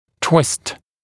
[twɪst][туист]кручение, скручивание, продольный изгиб; скручивать, формировать продольный изгиб